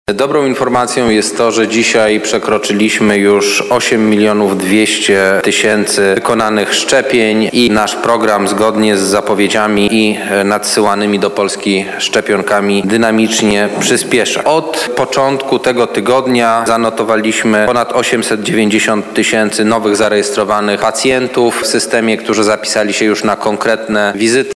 konferencja
O tym, ile osób poddało się już zabiegowi, mówi pełnomocnik rządu do spraw szczepień Michał Dworczyk.
dworczyk-1.mp3